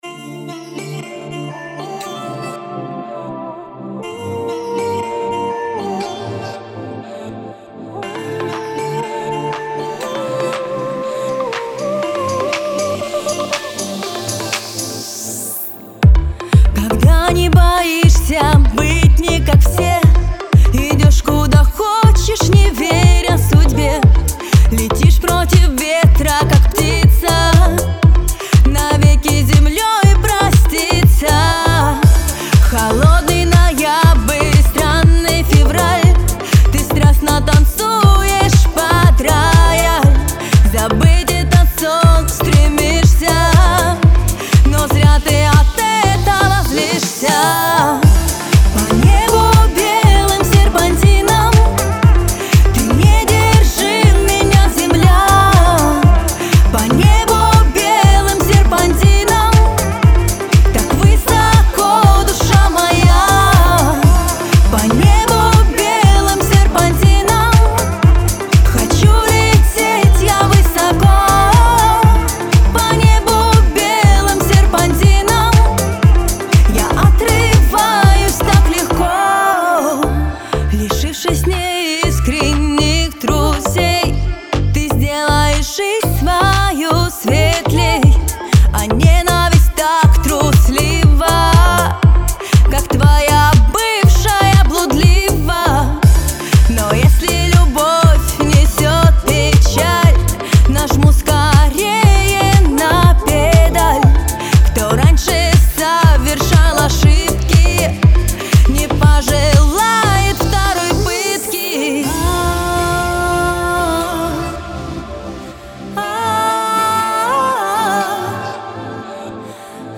дебютную композицию певицы
обладает чувственным и звонким голосом.